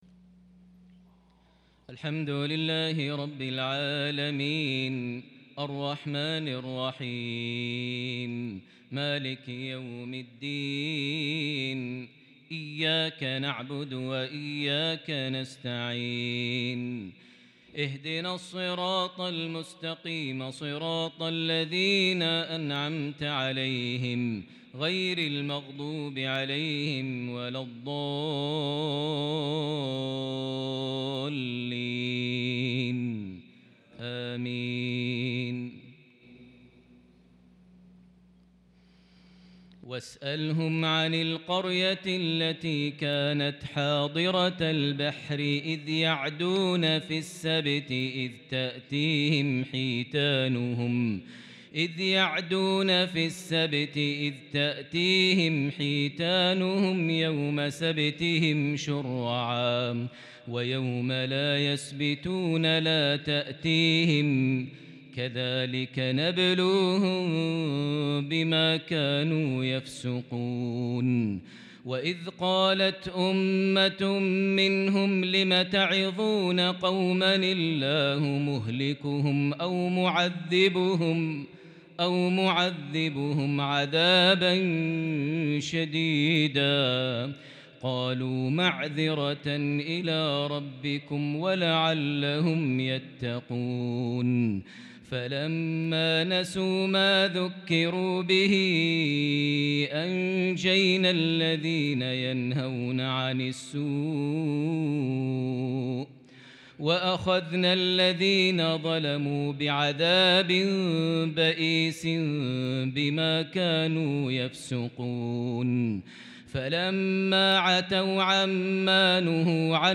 صلاة العشاء من سورة الأعراف |الخميس 2 صفر 1443هـ | lsha 9-9-2021 prayer from Surah Al-Araf 163-174 > 1443 🕋 > الفروض - تلاوات الحرمين